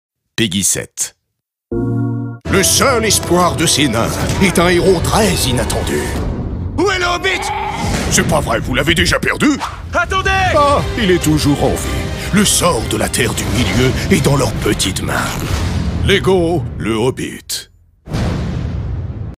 Interprétation voix-off de vieil homme sage pour "Lego The Hobbit"
Vieux sage, amusement.
Spot Tv pour le jeu vidéo « Lego The Hobbit » enregistré chez Studio Time.
Pour cette réalisation, j’ai dû adapter ma voix pour incarner un personnage de vieil homme sage. C’est un rôle qui nécessite une voix grave, qui évoque à la fois la sagesse et le jeu, tout en restant accessible pour les enfants.